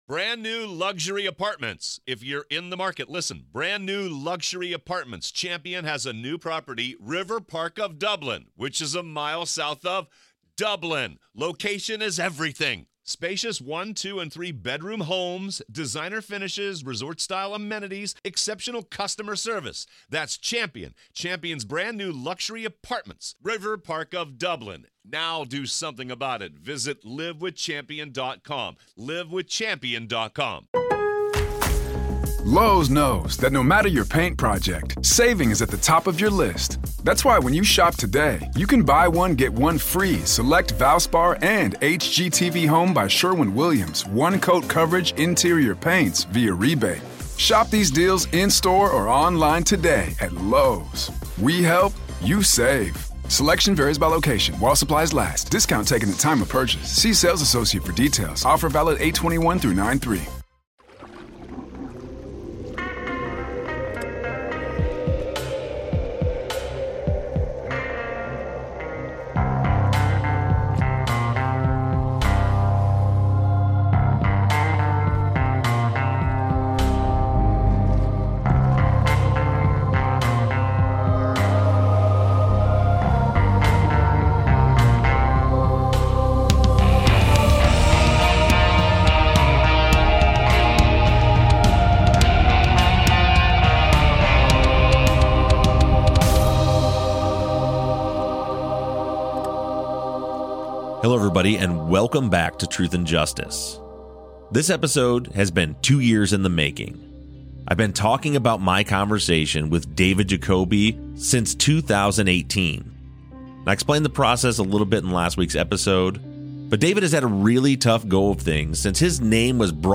True Crime, Documentary, Society & Culture